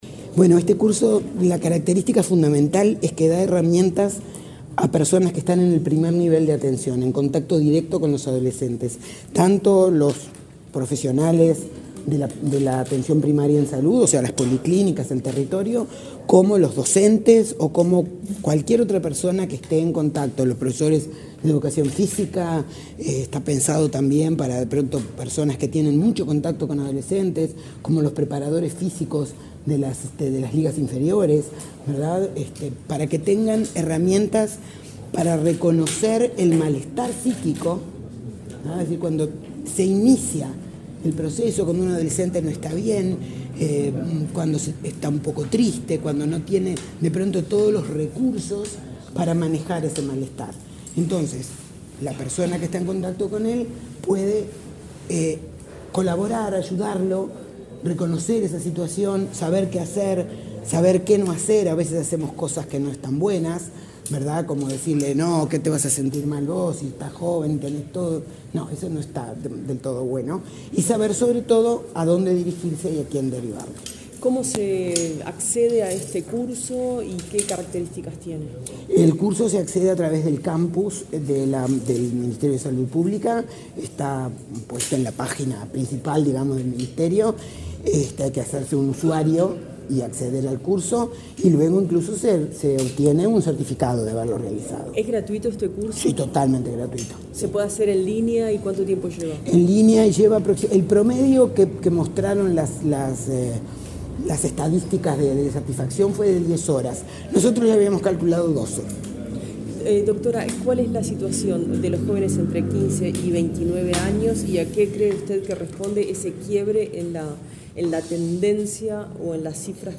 Declaraciones de la directora de Infancia y Juventud del MSP, Laura Batalla
Este martes 19 en la sede del Ministerio de Salud Pública (MSP), se realizó el acto de relanzamiento del curso Prevención, abordaje y Posvención del Riesgo Suicida en Adolescentes. Luego, la directora de Infancia y Juventud de la referida secretaría de Estado, Laura Batalla, explicó a la prensa la importancia de la temática.